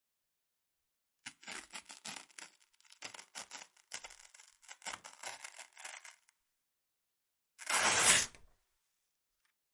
窗帘
描述：在钢栏杆上拉窗帘。慢，快等
Tag: 开口 打开 刮下 关闭 关闭 栏杆 窗口 研磨 磨削 OWI 窗帘 刷涂